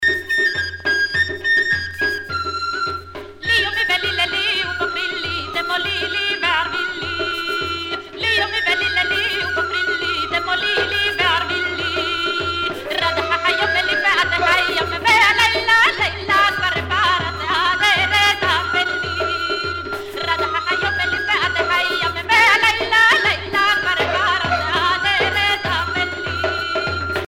Chansons douces et chansons d'amour